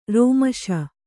♪ rōmaśa